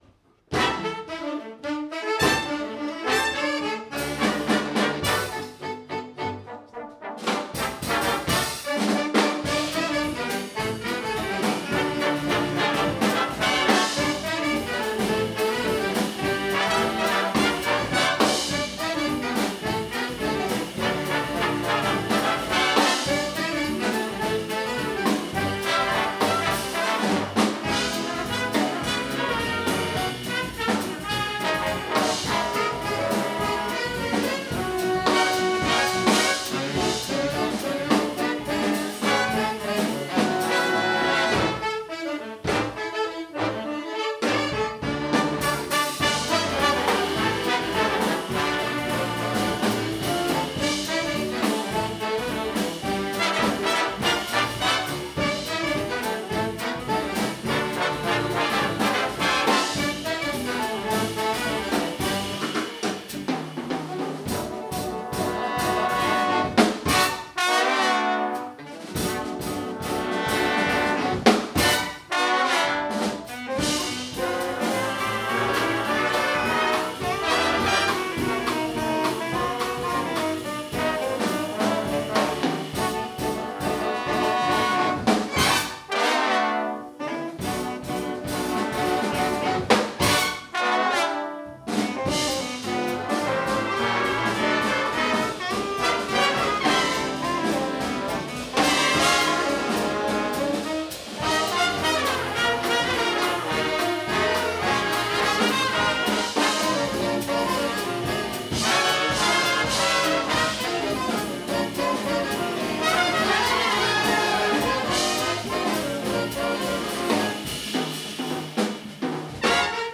Vi garanterer, at I kommer i julestemning når I hører de jazzsvingende juleklassikere, flere af dem med vokal-indslag.
Udstyret er én digital stereo mikrofon, ikke en studieoptagelse !